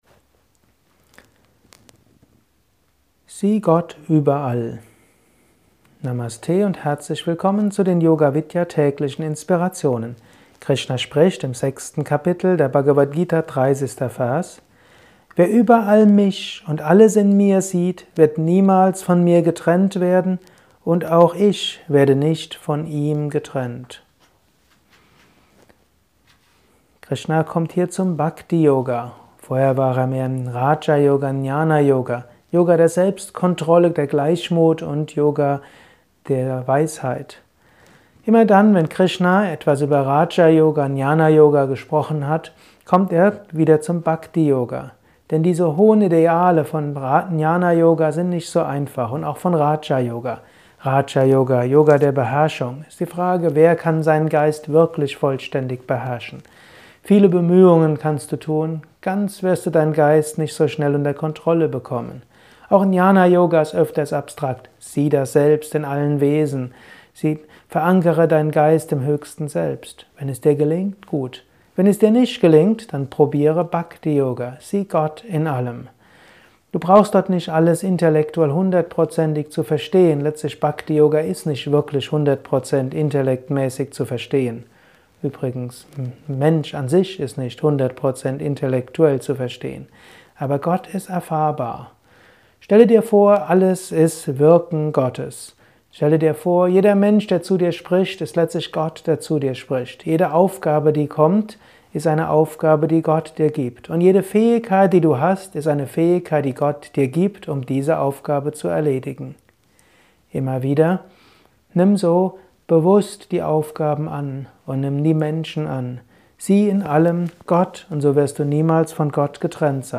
Kurzvortrag über die Bhagavad Gita